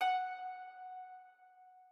harp1_8.ogg